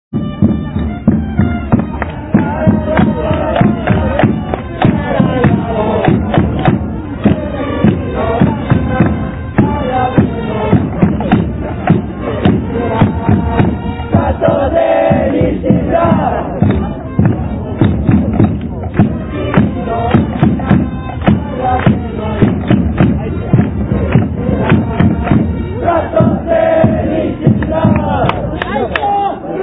- 過去に使われていた応援歌 -